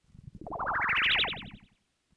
Electro Frog.wav